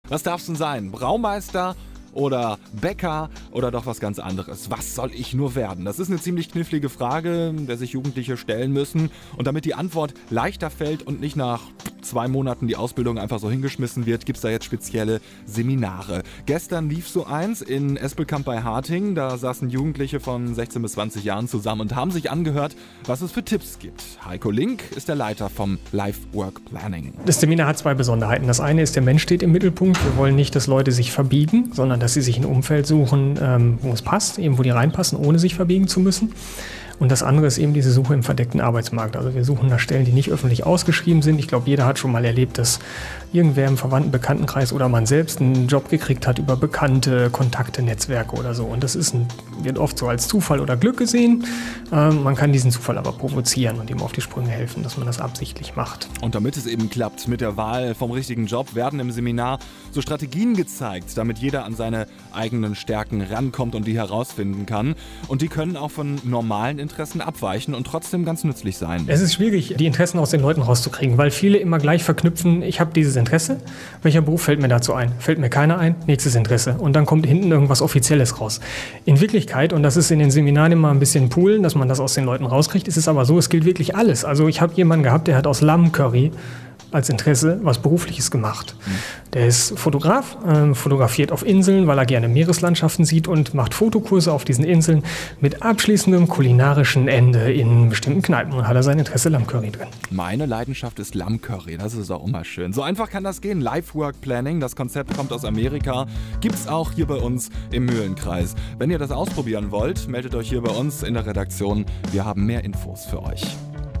Radio Interviews
Mitschnitt eines Interviews von einem L/WP-Impulstag, der in Zusammenarbeit mit dem Arbeitgeberverband Minden-Lübbecke und der Firma Harting durchgeführt wurde.